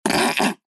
Звуки поноса